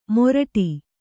♪ moraṭi